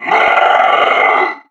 Mercenary_Park_Yeti_statue_growl3.wav